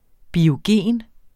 Udtale [ bioˈgeˀn ]